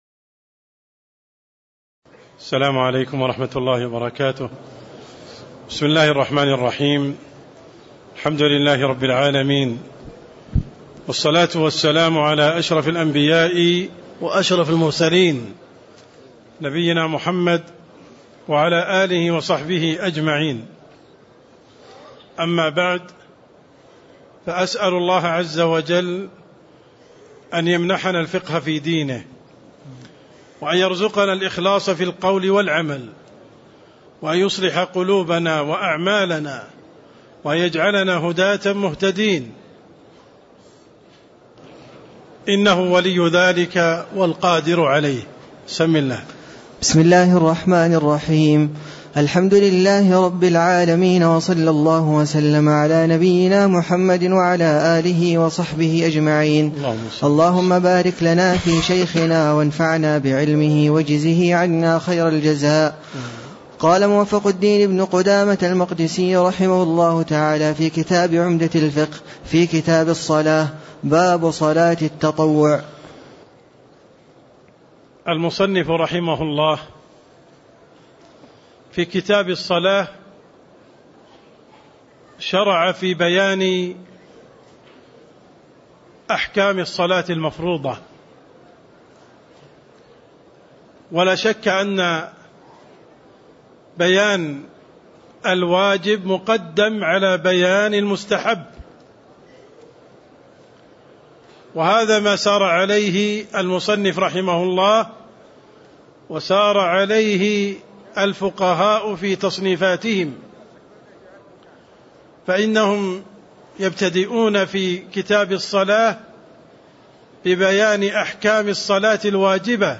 تاريخ النشر ١٢ جمادى الأولى ١٤٣٦ هـ المكان: المسجد النبوي الشيخ